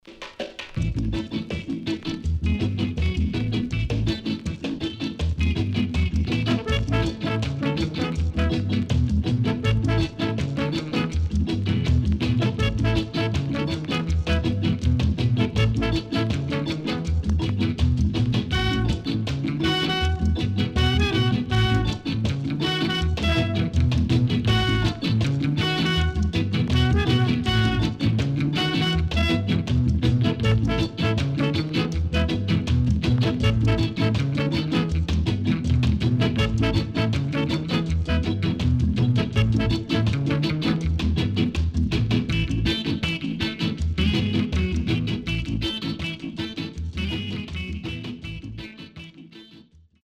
Nice Early Reggae Vocal & Nice Inst.W-Side Good
SIDE A:うすいこまかい傷ありますがノイズあまり目立ちません。